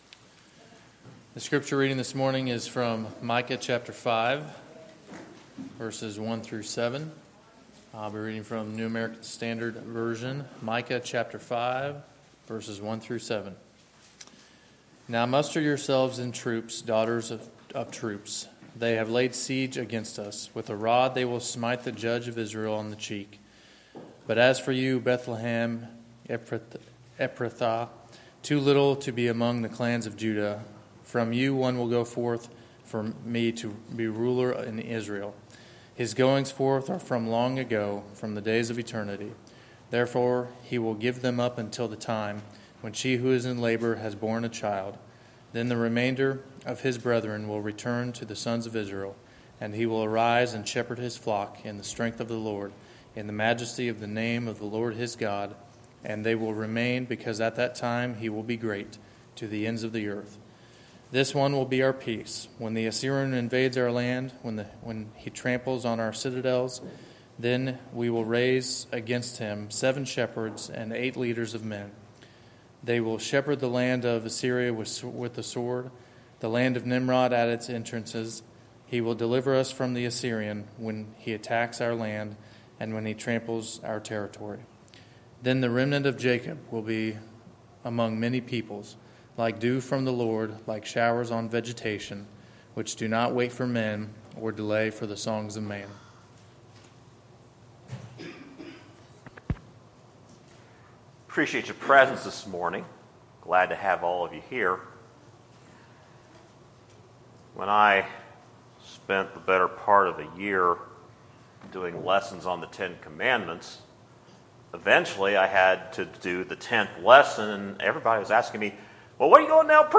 Sermons, August 7, 2016